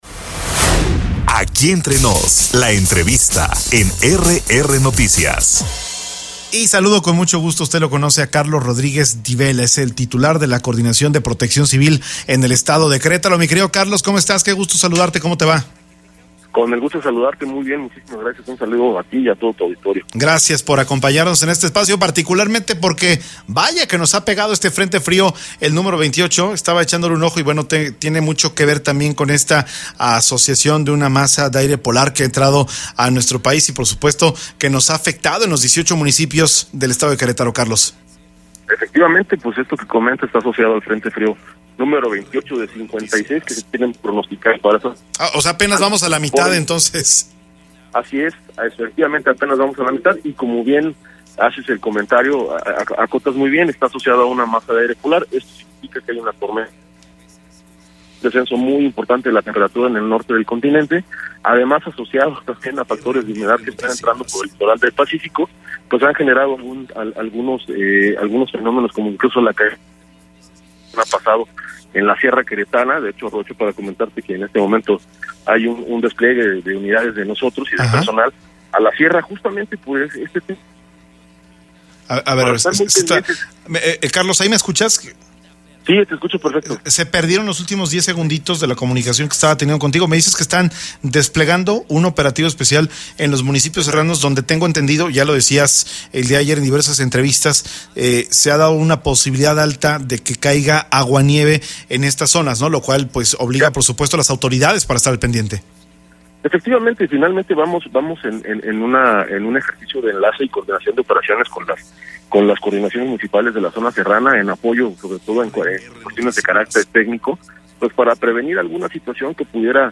EntrevistasOpiniónPodcast